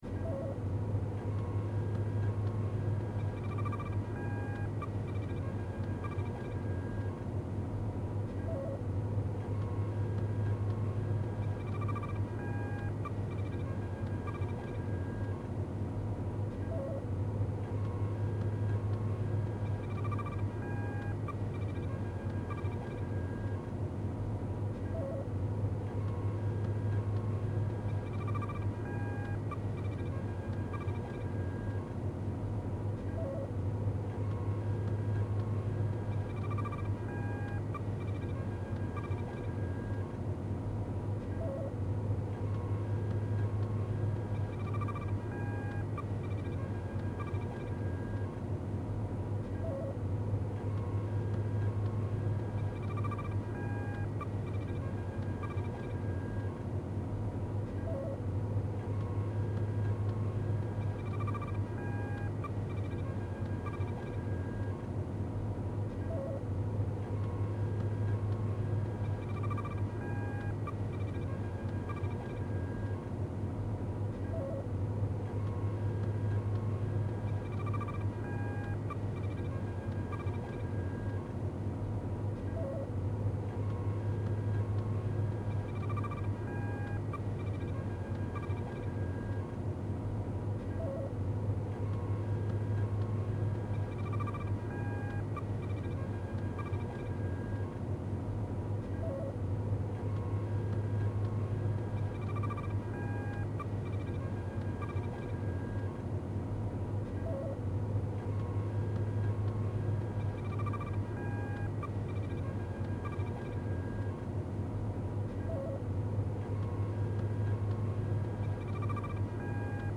medicalcenter.ogg